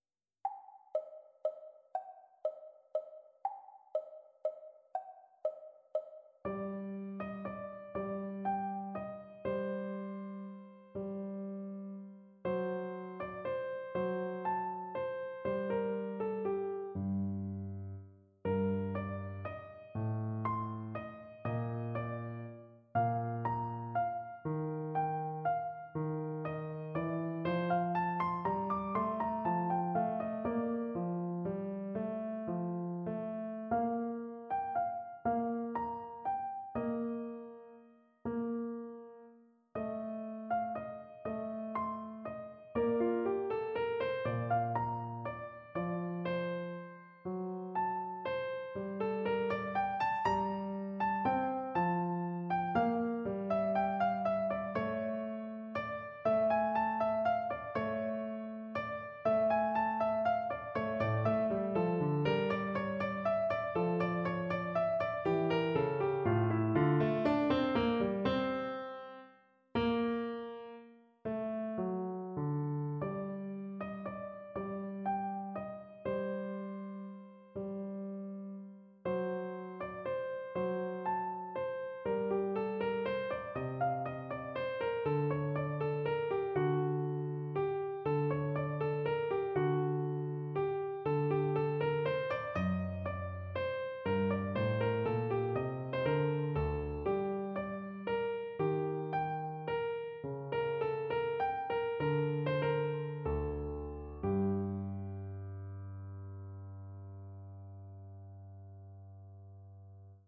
Without Pianist 1